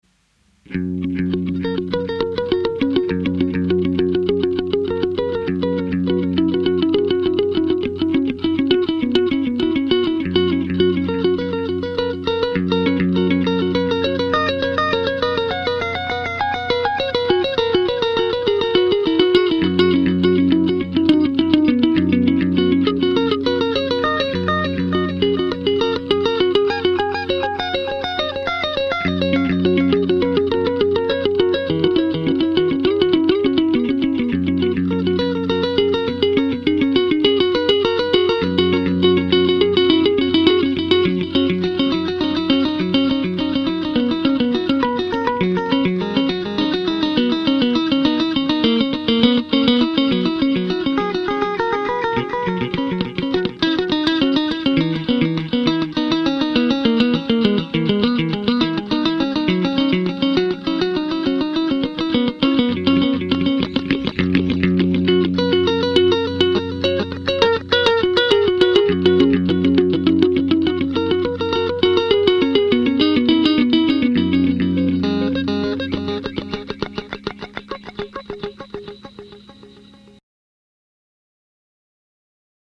MOONPATROL - HIGH ENERGY ROCK'N'ROLL
Klampfe einstecken, Schlagzeug aussuchen und Aufnahme drücken.
Damit wäre die Stimmung der Nacht dann eingefangen und die Wache wurde zum 6-Stunden-Jam mit den Maschinen.